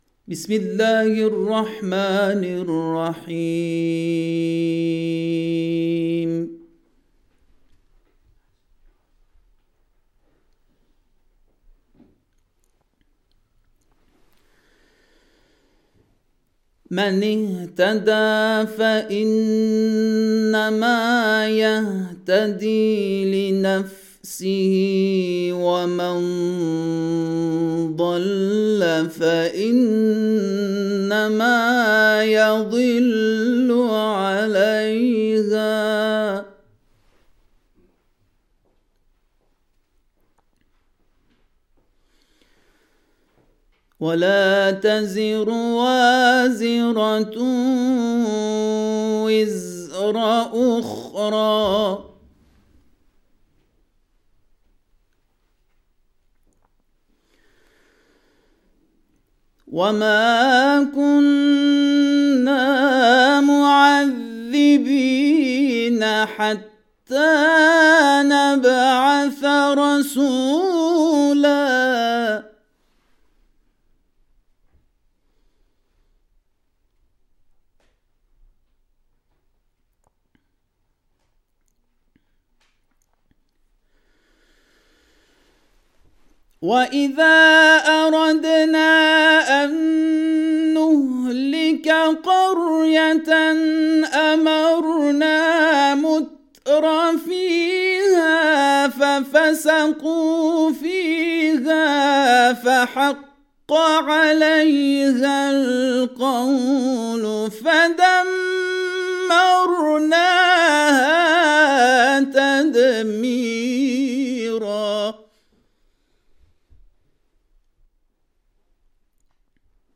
برچسب ها: تلاوت قرآن ، اردبیل